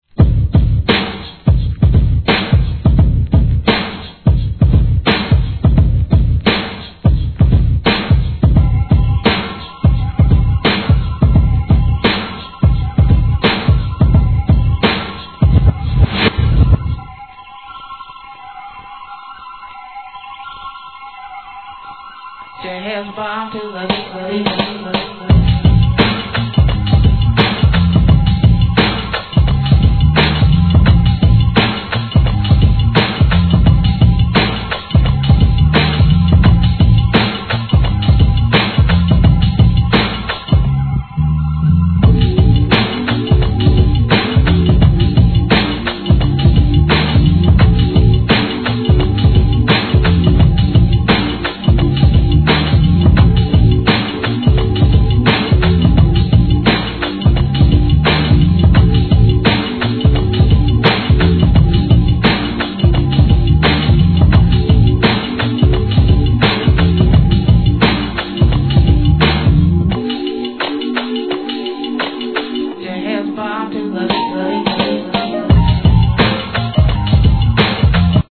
HIP HOP/R&B
N.Y.アブストラクト・ブレイクビーツ!